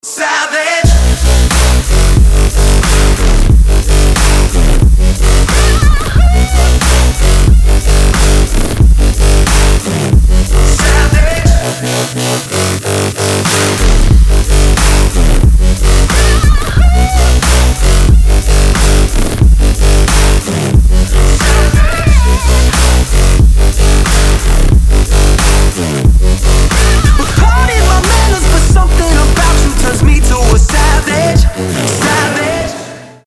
мощные
low bass
Bass music
Стиль: Future Bass, Electronic